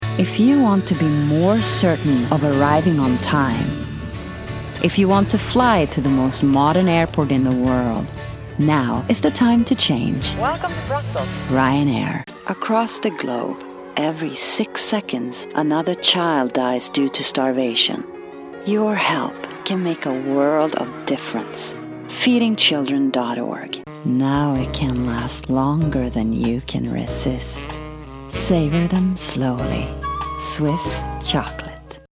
Swedish, North American, Positive, Warm, Smooth, Professional, Rich, Compassionate, Original, Worldly
Sprechprobe: eLearning (Muttersprache):